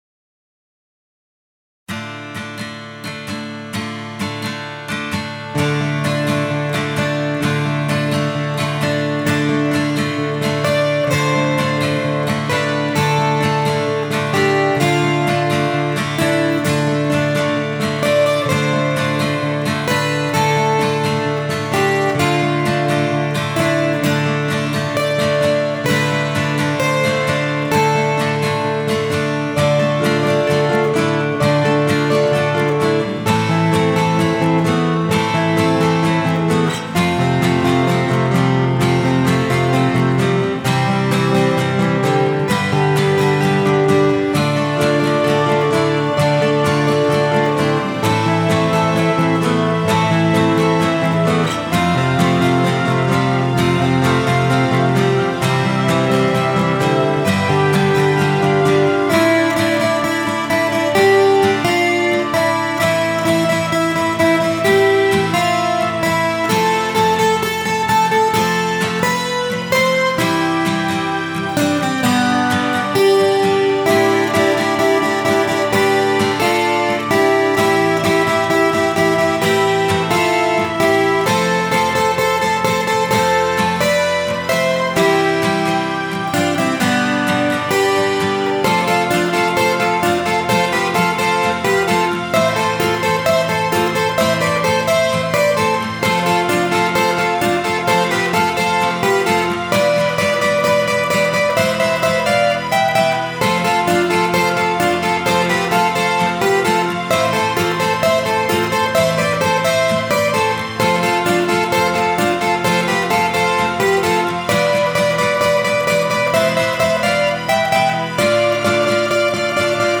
an instrumental